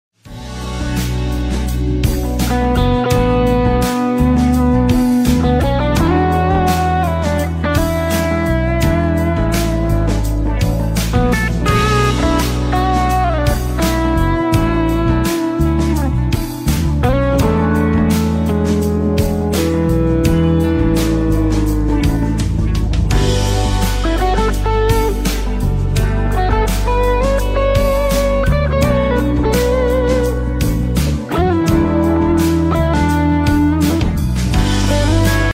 Guitar Improvisation Sound Effects Free Download